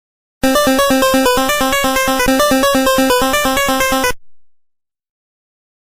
miss jingle